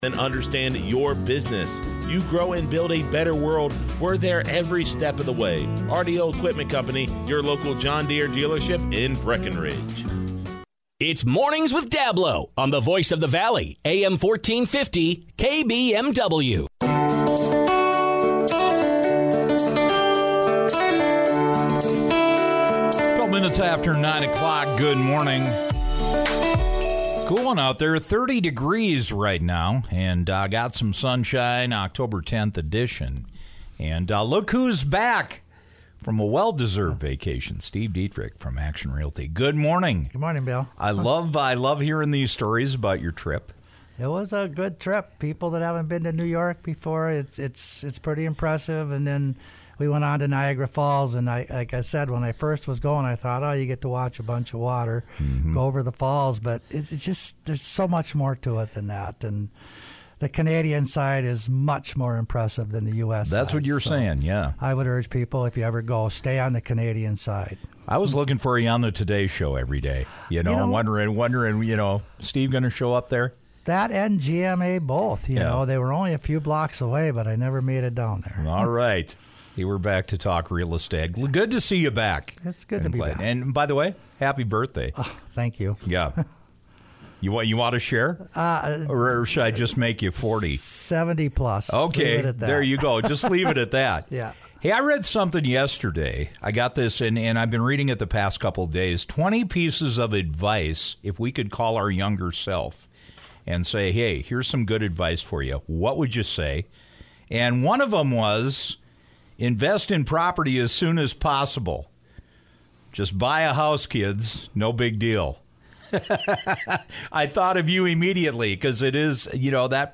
and  the pitfalls of home buying and interest rates on his Tuesday radio segment.